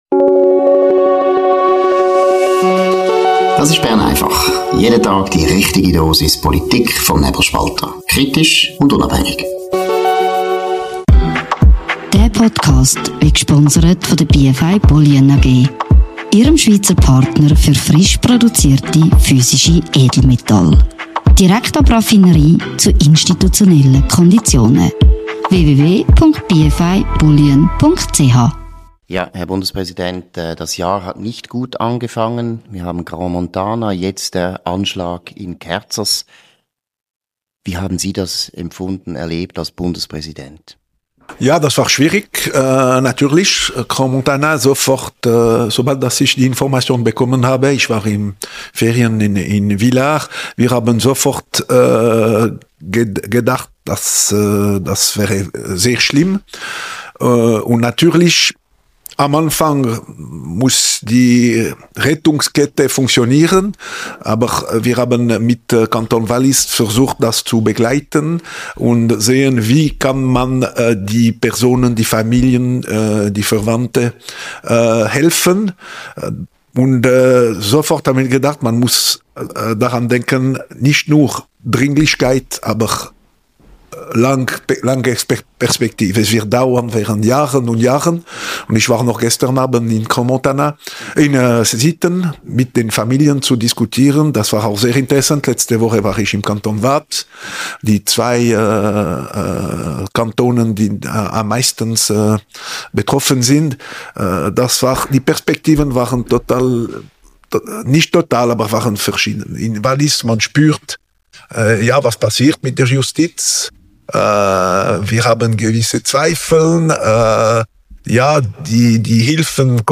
im Gespräch mit dem Bundespräsidenten